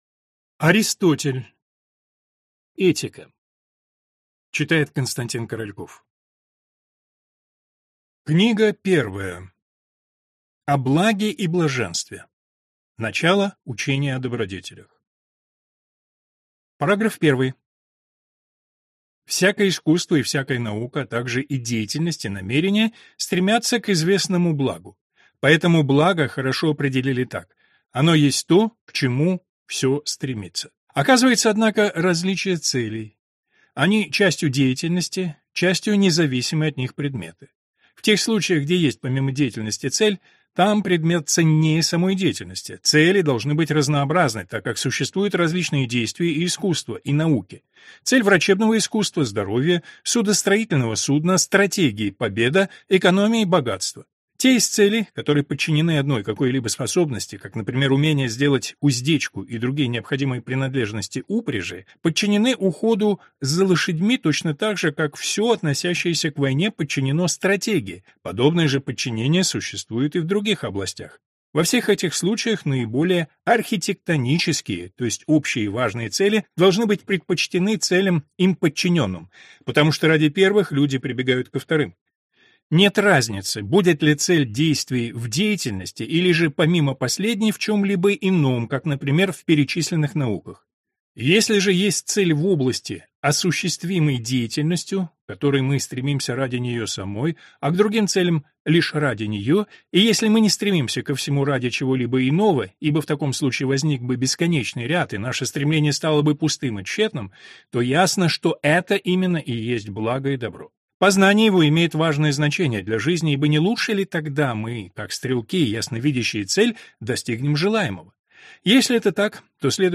Аудиокнига Этика | Библиотека аудиокниг